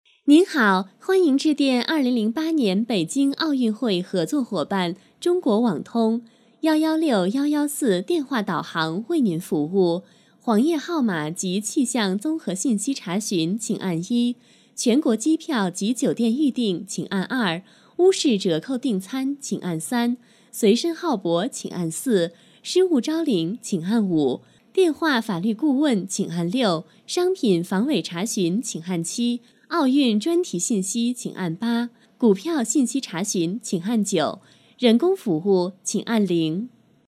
女声配音
彩铃女国33